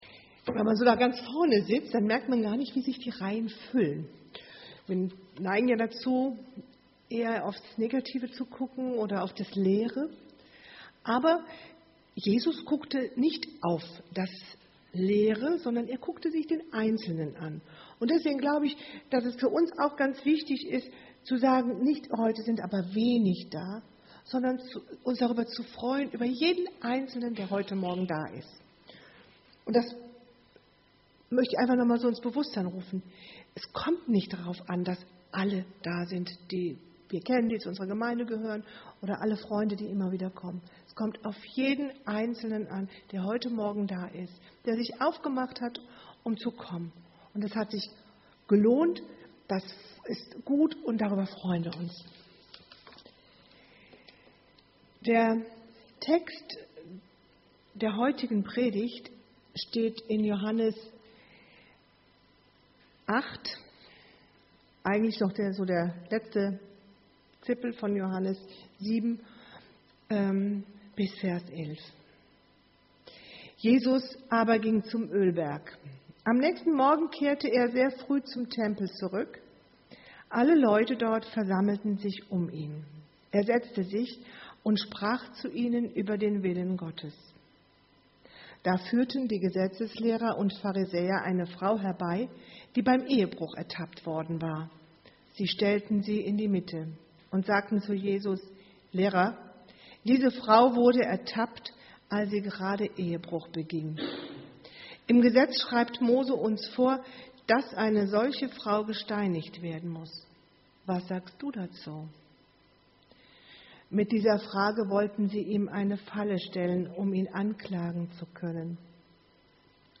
Evangelisch-freikirchliche Gemeinde Andernach - Predigt anhören